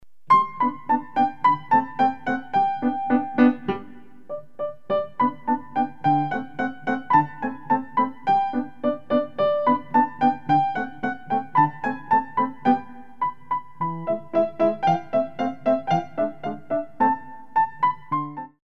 2 Tps rapide